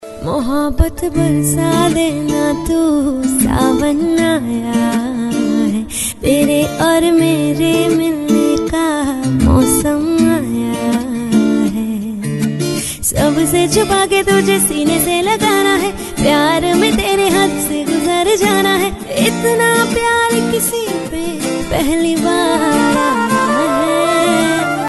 Female.mp3 Song Download Bollywood Mazafree